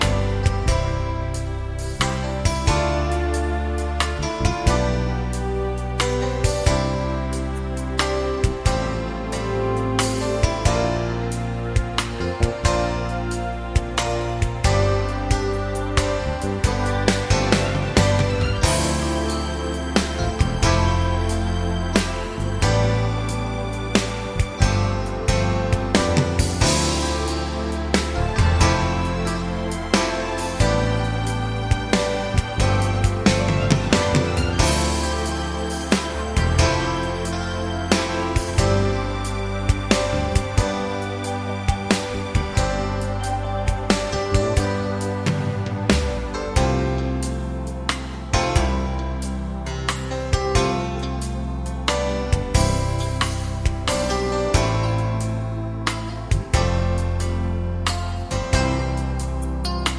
no Backing Vocals Soft Rock 4:24 Buy £1.50